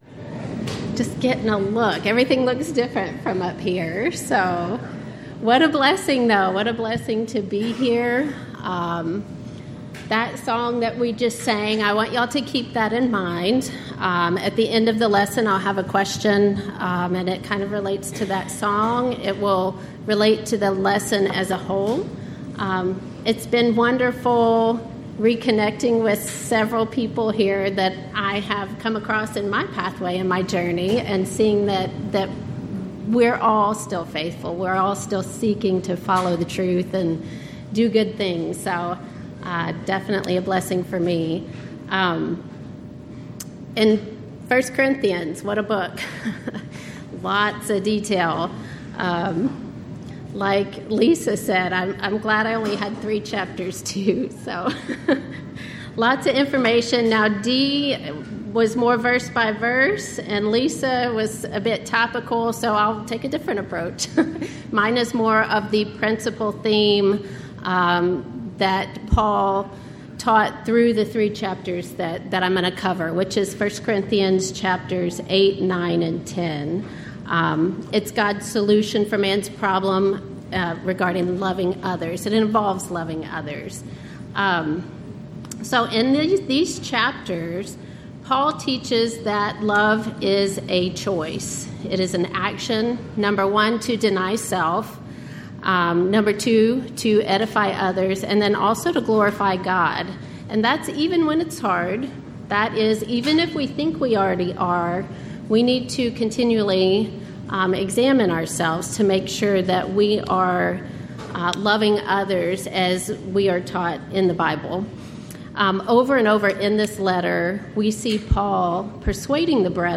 Event: 13th Annual Texas Ladies in Christ Retreat
Ladies Sessions